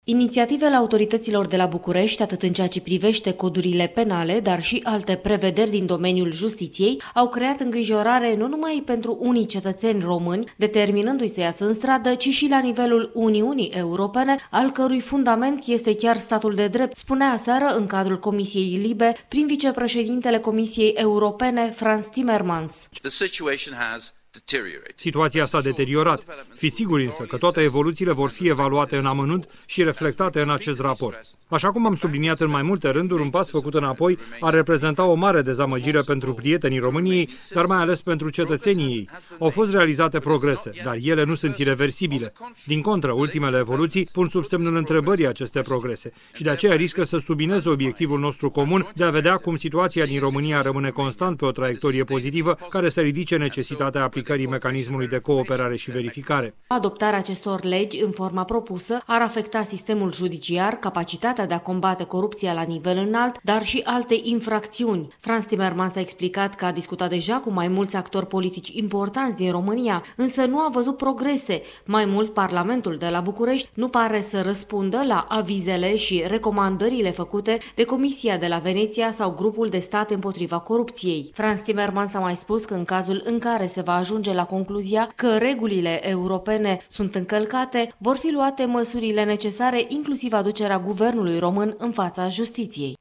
Trimisul nostru special la Strasbourg